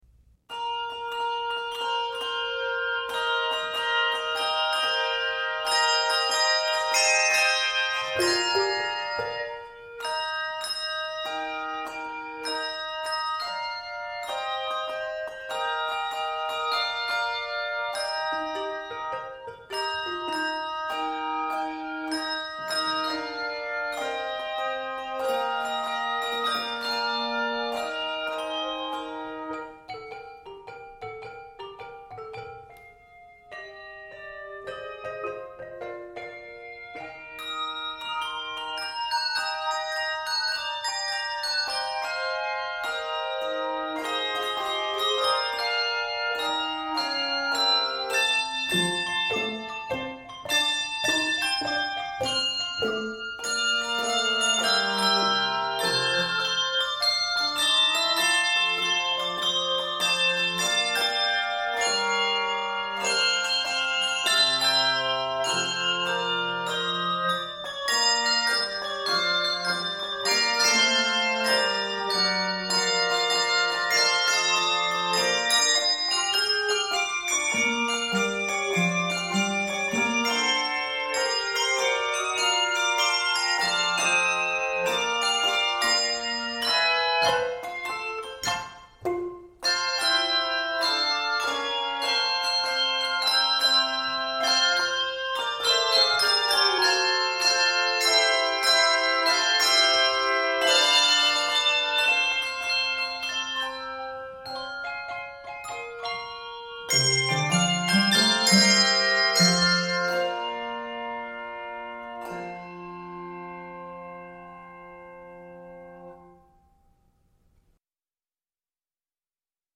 Keys of Eb Major, Bb Major, and F Major.
Composer: Traditional Spiritual
Octaves: 3-5